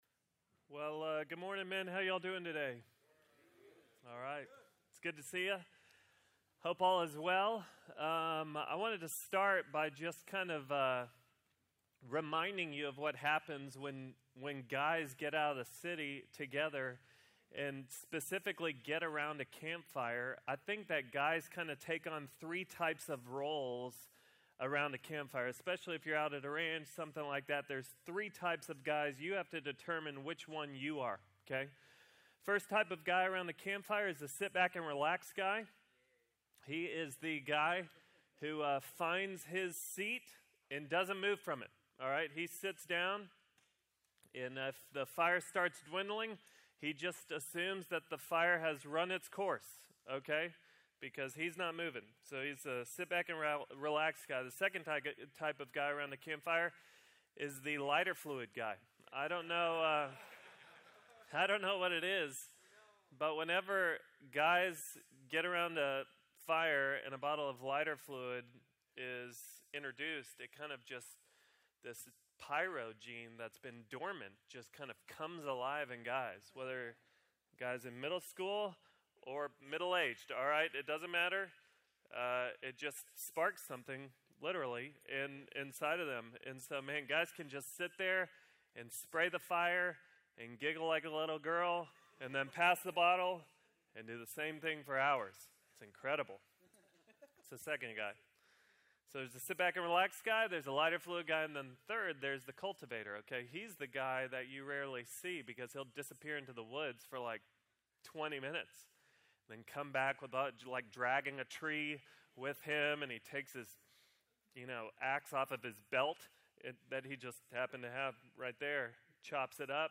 Mens Retreat 2016 Session 2 | Sermon | Grace Bible Church
GBC Men's Retreat Session 2, Frontier Camp 2016